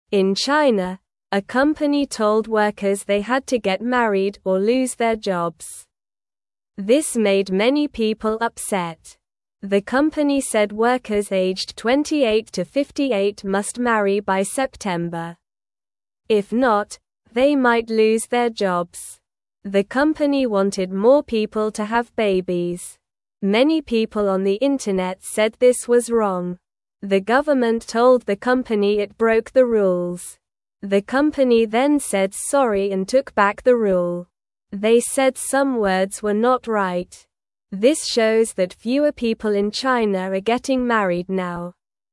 Slow
English-Newsroom-Beginner-SLOW-Reading-Company-Makes-Workers-Marry-or-Lose-Their-Jobs.mp3